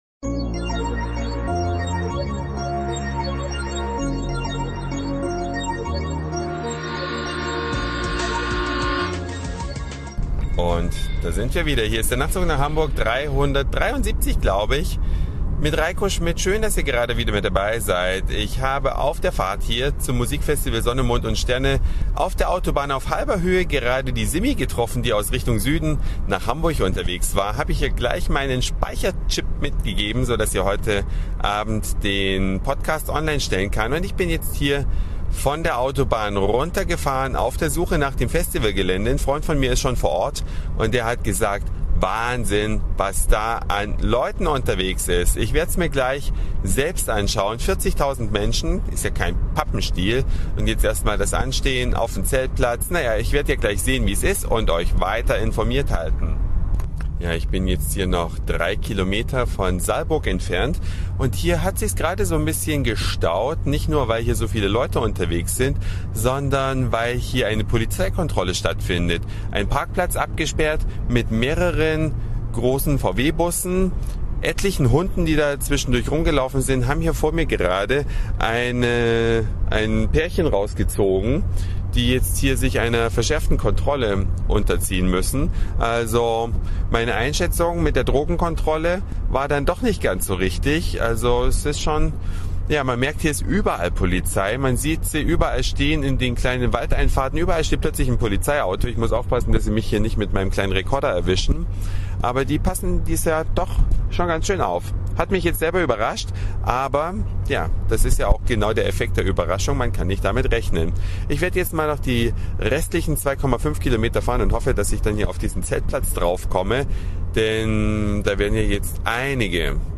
Sonne, Mond und Sterne, kurz SMS 2006, Freitag abend bei der Ankunft.